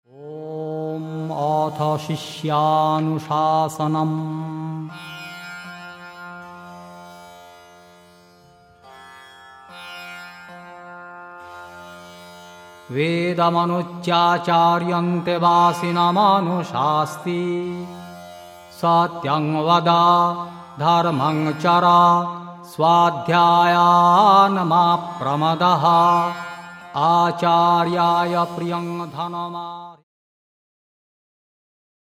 (Vedic mantras in chant and song)